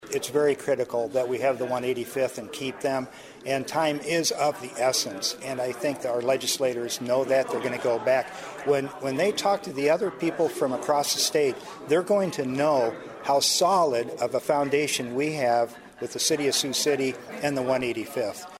DAN MOORE SAYS THAT PROJECT IS CRITICAL TO KEEP THE GUARD AND ITS 955 PERSONNEL IN SIOUX CITY: